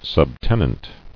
[sub·ten·ant]